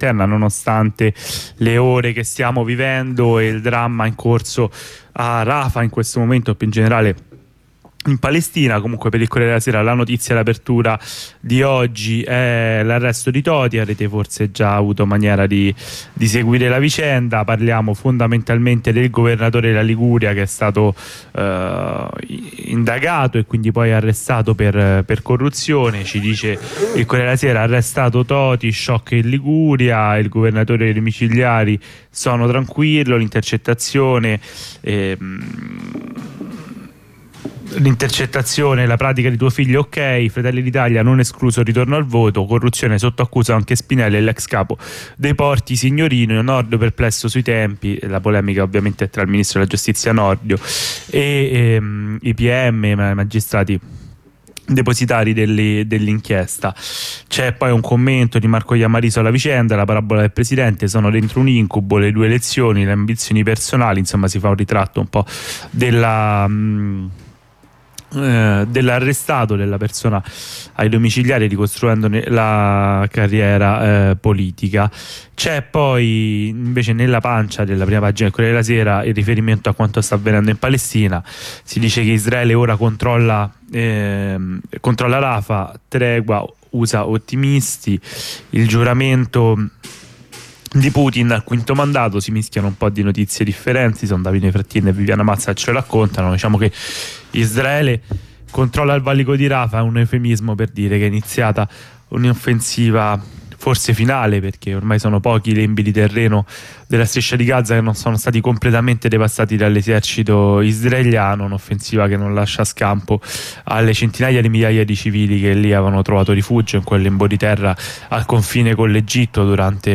La lettura dei quotidiani di oggi